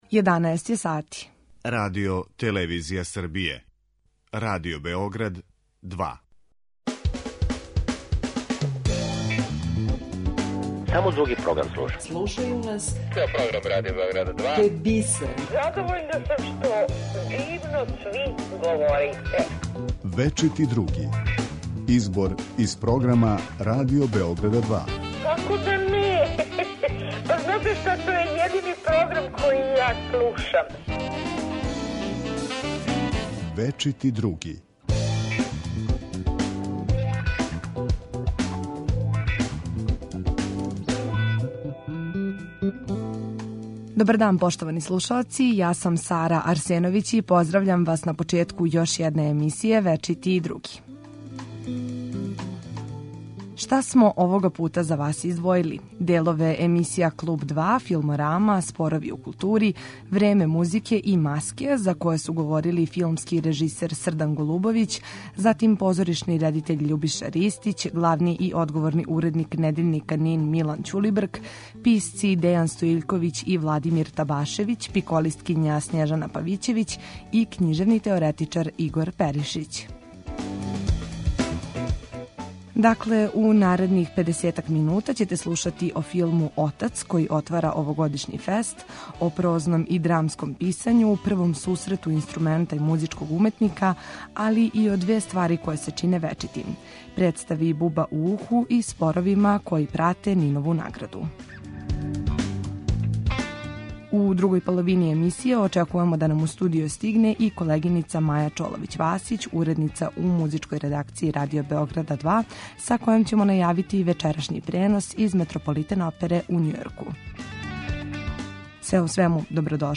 Избор из програма Радио Београда 2